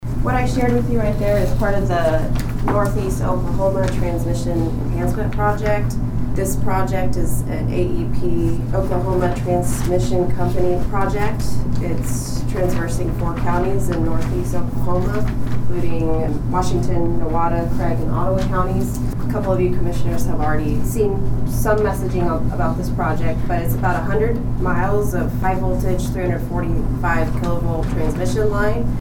During Monday morning's meeting of the Nowata County Commissioners, the board heard a presentation from representatives from American Electric Power (AEP), regarding a transmission project in Northeast Oklahoma.